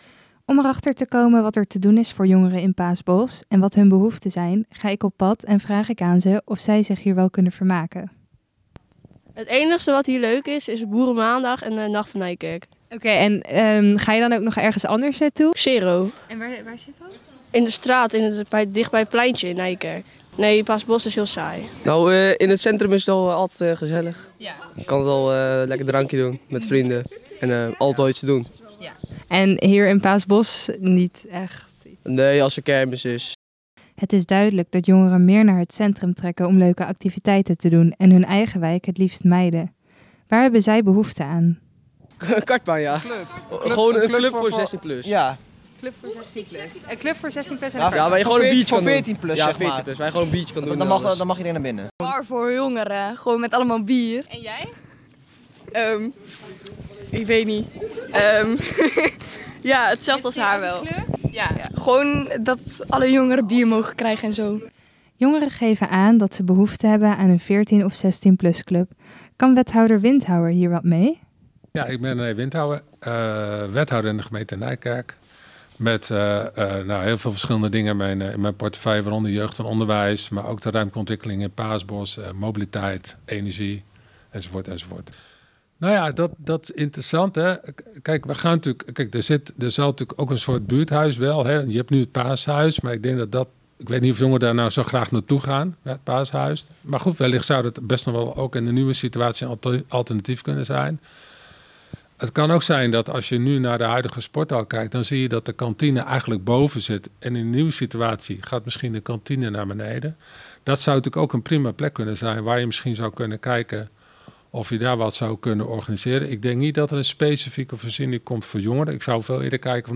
NIJKERK – Wethouder René Windhouwer van de gemeente Nijkerk vertelt over de faciliteiten voor jongeren in de wijk Paasbos en welke faciliteiten er voor jongeren in de toekomst in Paasbos zullen komen.